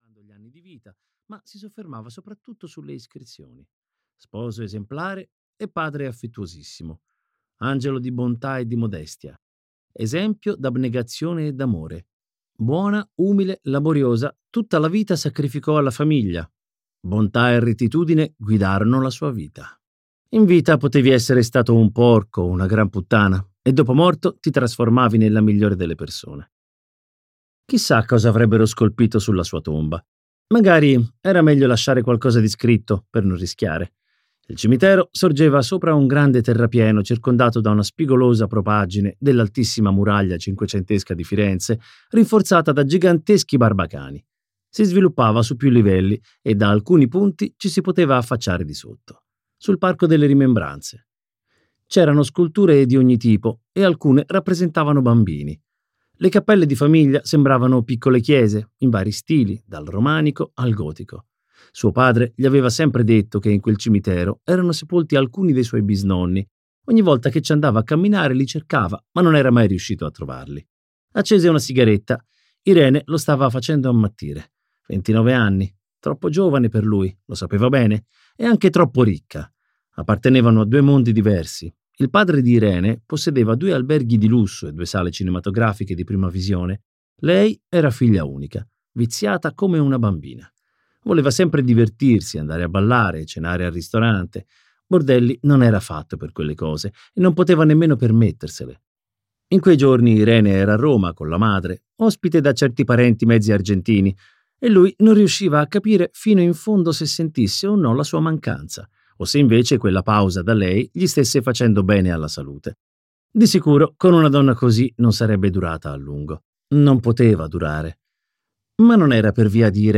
"La casa di tolleranza" di Marco Vichi - Audiolibro digitale - AUDIOLIBRI LIQUIDI - Il Libraio